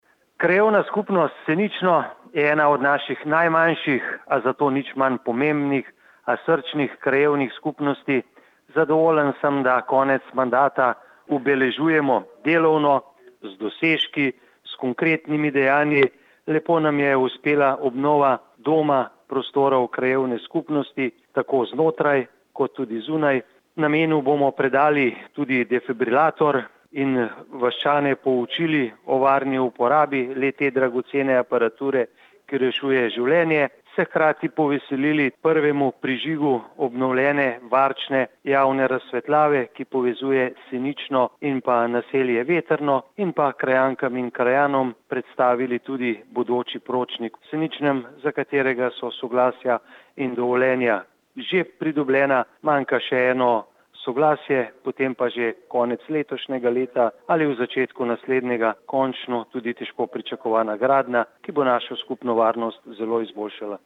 izjava_mag.borutsajoviczupanobcinetrzicoprenovidomakrajanovsenicno.mp3 (1,5MB)